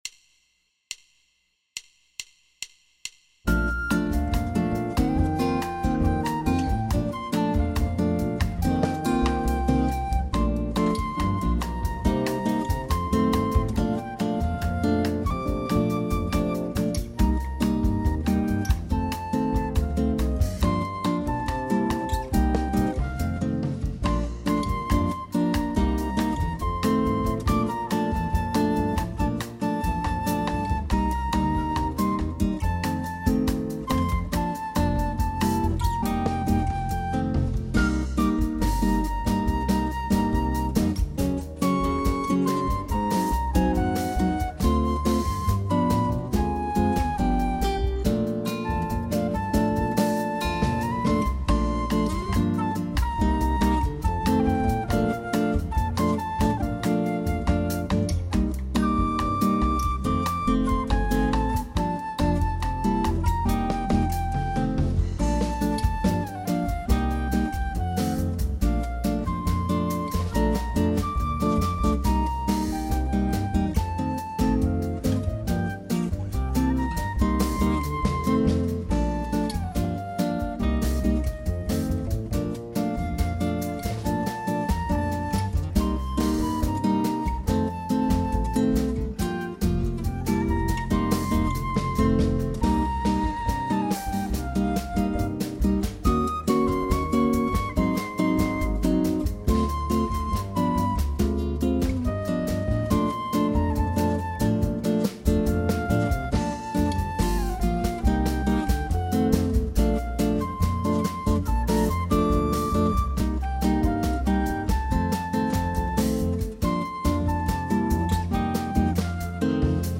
Home > Music > Jazz > Smooth > Medium > Laid Back